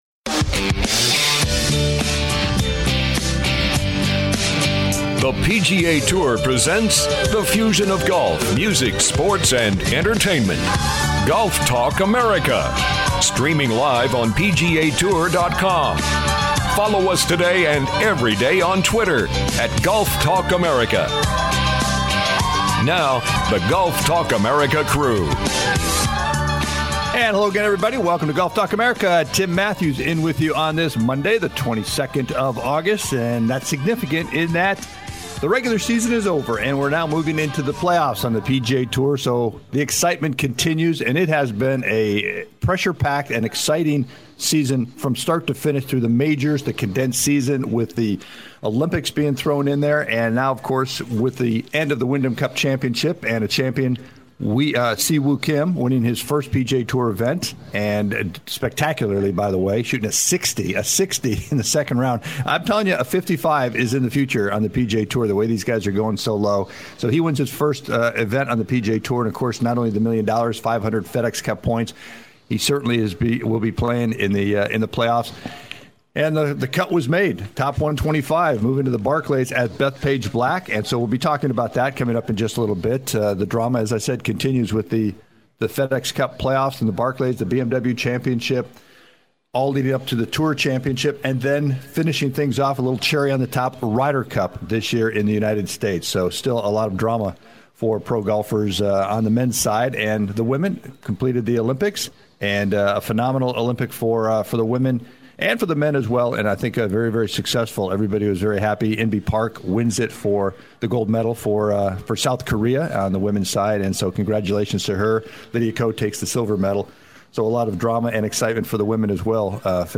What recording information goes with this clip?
GTA "LIVE" in The French Lick Hotel Lobby with special guests!